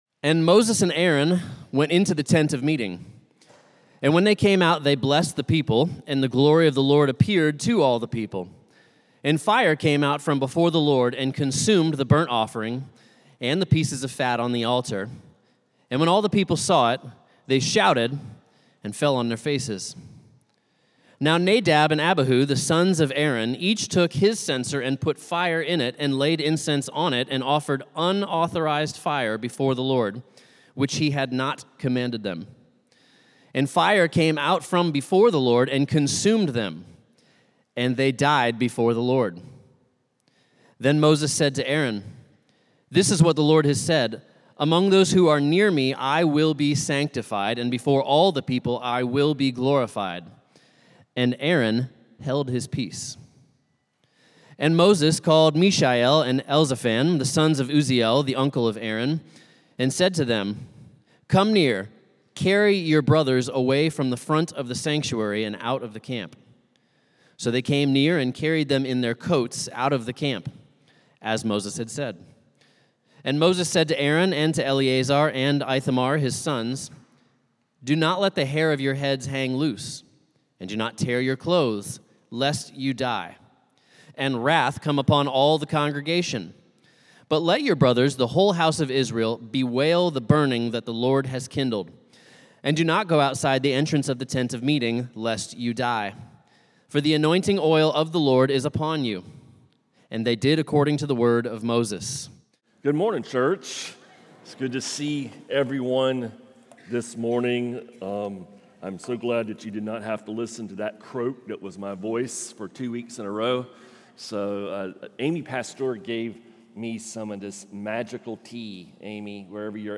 A message from the series "Holy God Holy People."